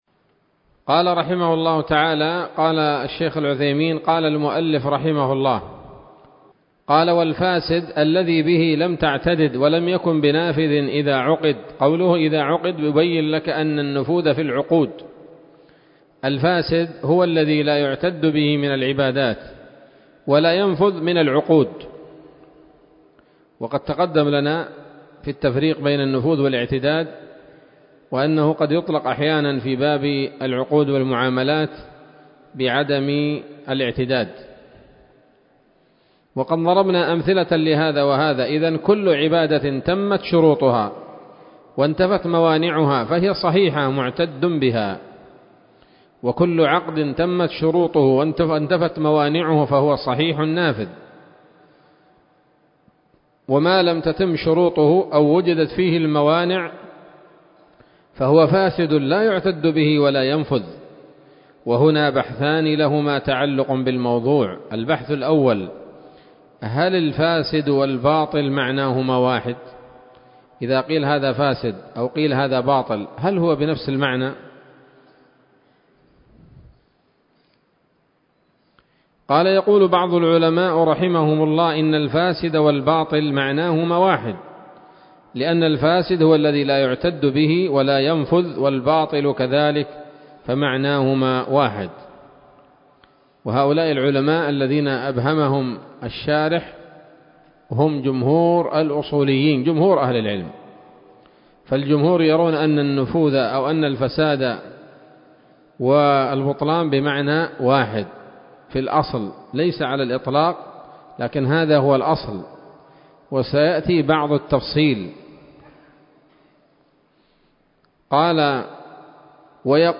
الدرس السابع عشر من شرح نظم الورقات للعلامة العثيمين رحمه الله تعالى